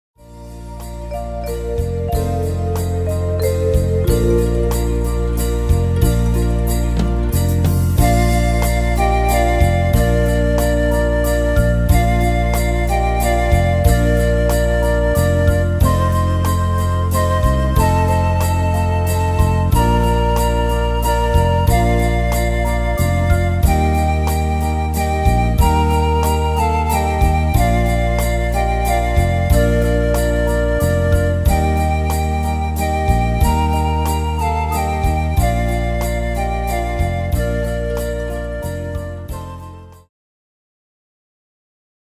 Tempo: 92 / Tonart: Bb-Dur